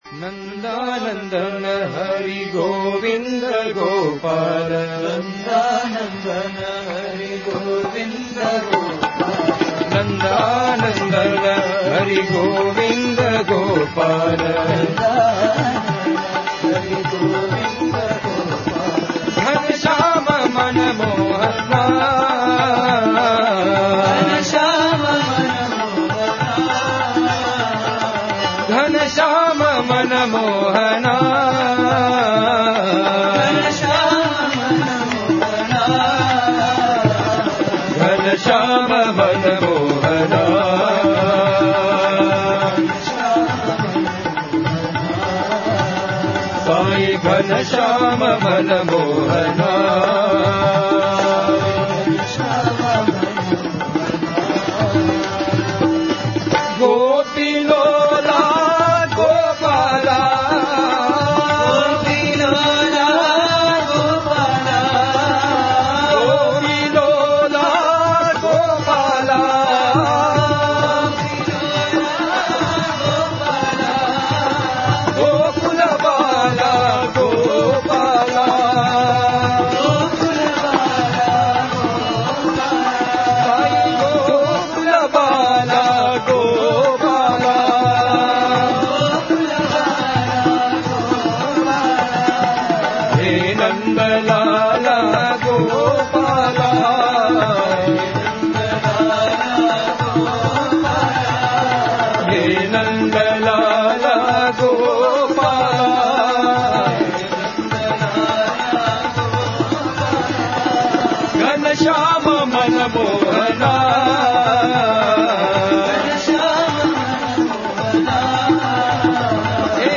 Бхаджан на день